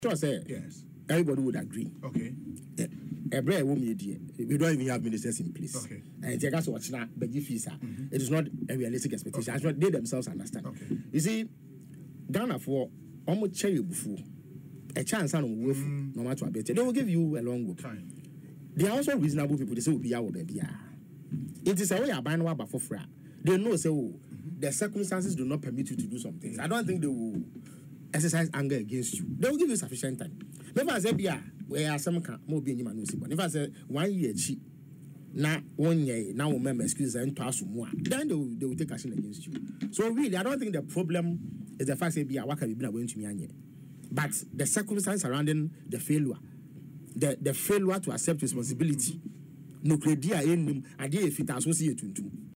Mr. Ofosu disclosed this in an interview on Asempa FM’s Ekosii Sen after he was asked when the policy will take effect.